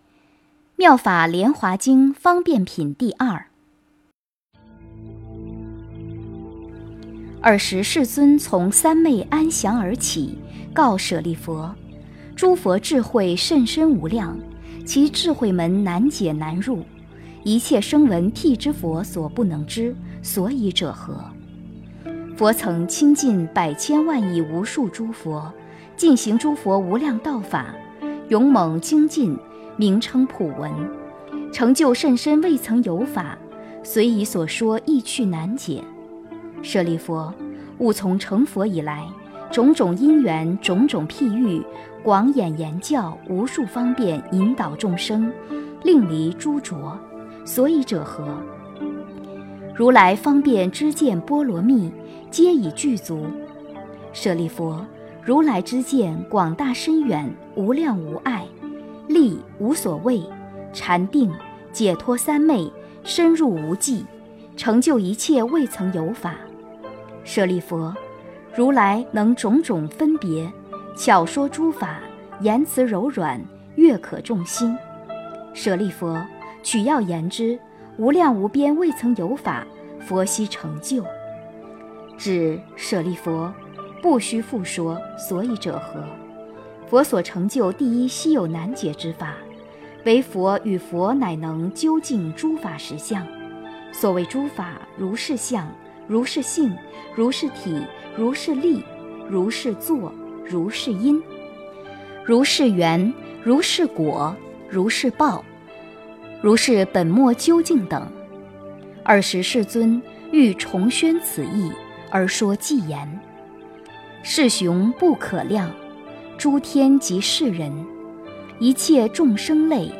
《妙法莲华经》方便品第二 - 诵经 - 云佛论坛